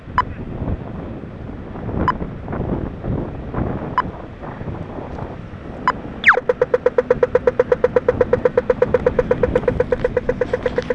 Dublin Pedestrian Crossing Lights
It started to pip and when it went green I went berzerk.
What we see here is a spectral representation of the distinctive gliding "pew" tone the light makes as it goes green.